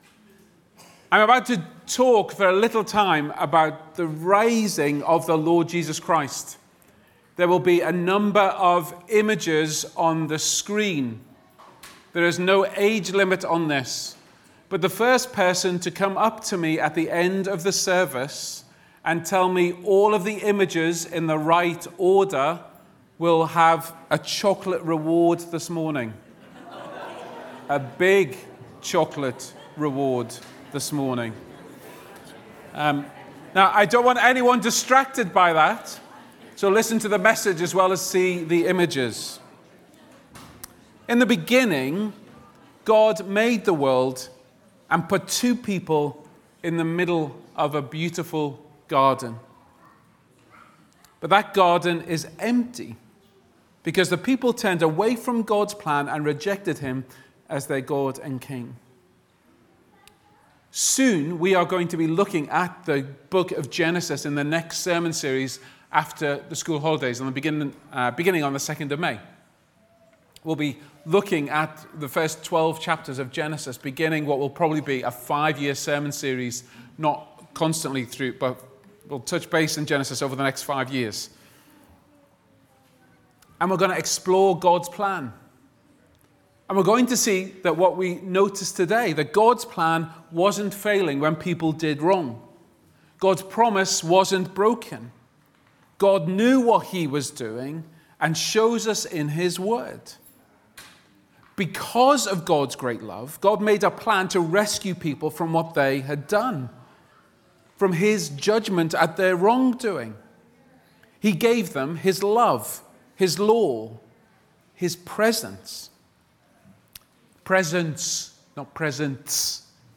Sermons | Titirangi Baptist Church
Guest Speaker